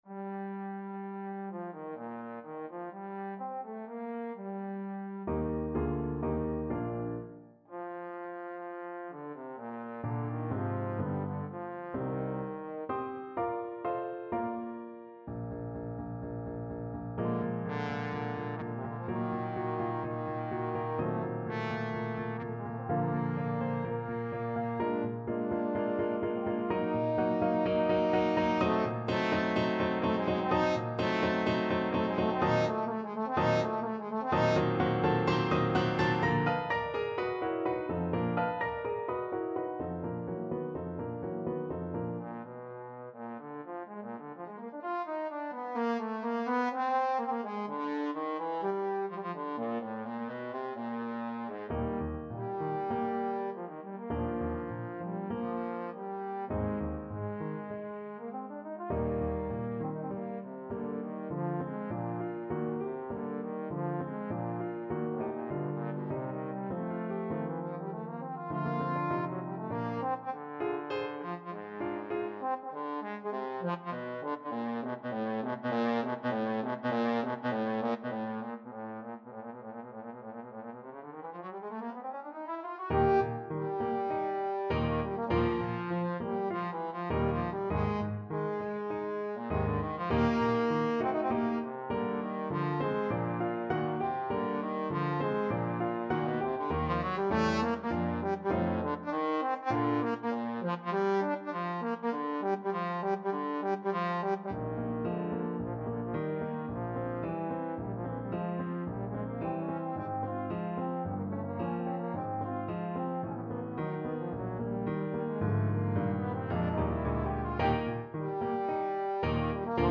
4/4 (View more 4/4 Music)
Allegro moderato =126 (View more music marked Allegro)
Ab3-G5
Classical (View more Classical Trombone Music)